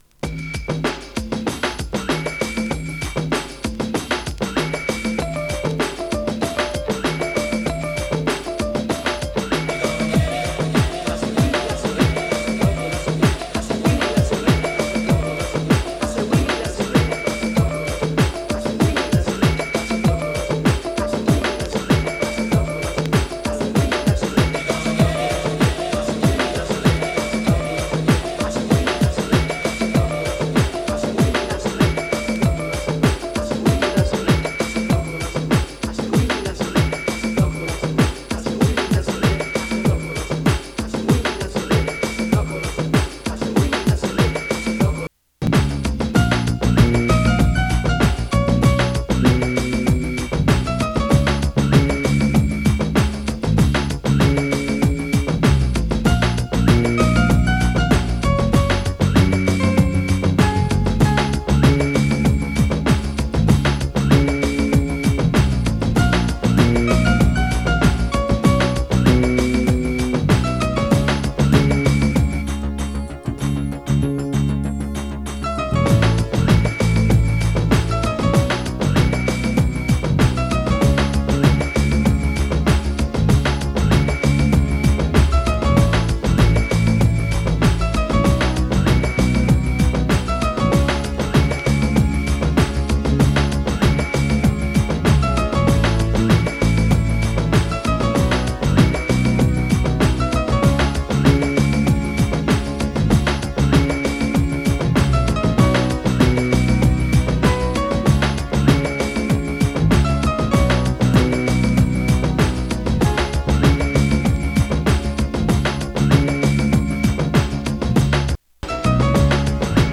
民族 壮大 グランドビート
トライバルで壮大な雰囲気が漂うグランドビート！